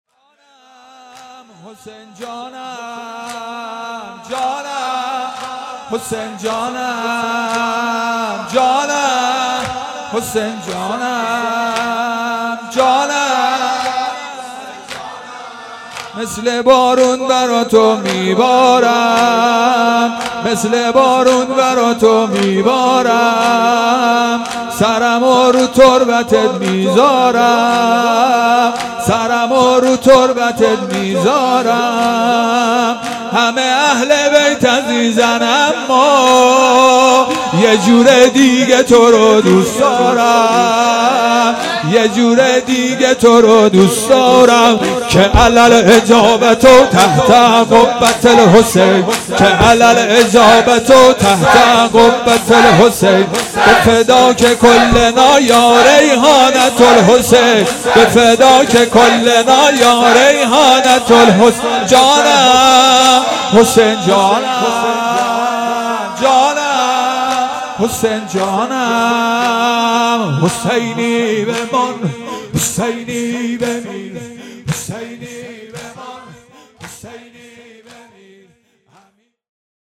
شب چهارم محرم الحرام 1441